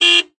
carhorn.ogg